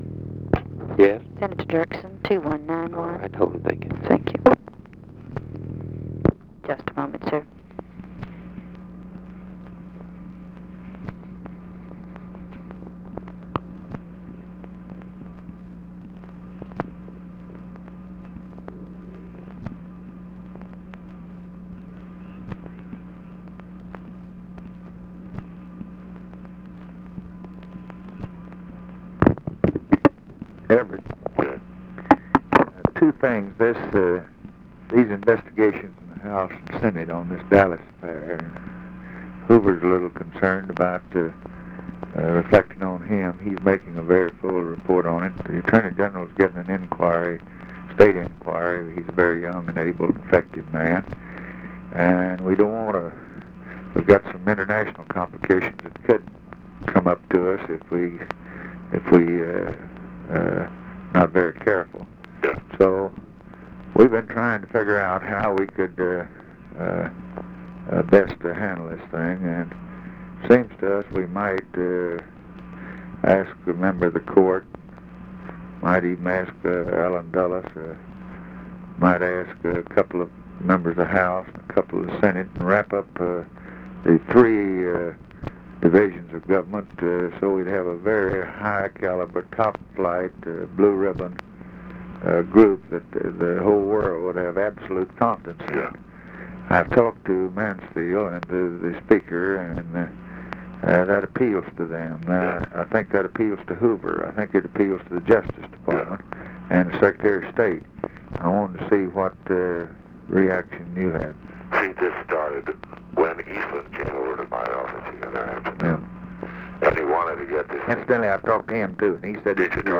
Conversation with EVERETT DIRKSEN, November 29, 1963
Secret White House Tapes